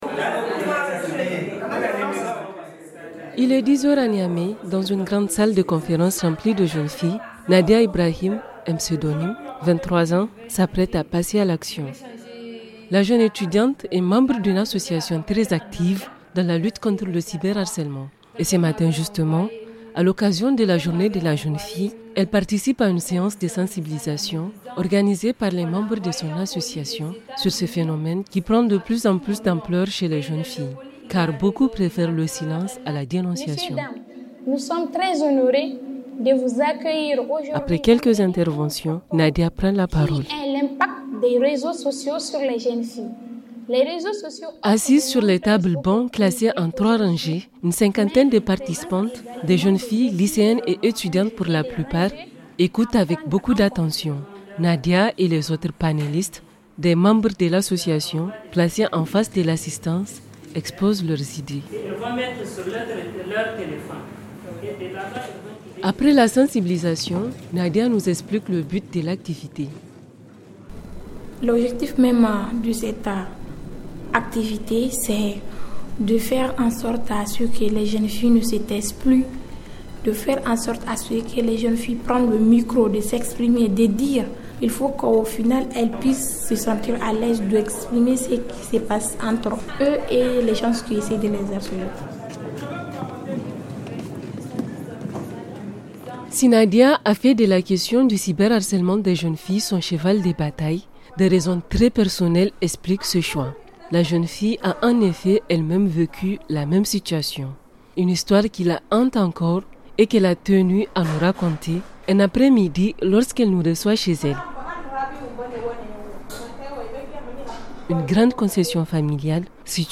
Pour précision, la voix de cette jeune fille a été modifiée.
Documentaire